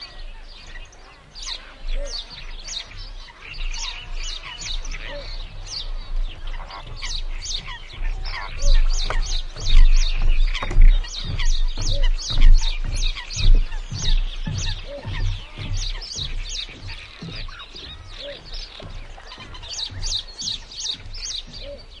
描述：低沉的脚步声在木制的猫道上响起，背景是鸟（莺、麻雀、库特、椋鸟）的叫声。如果你注意，你会发现路人都是观鸟者（哈哈）
标签： 氛围 现场记录 性质
声道立体声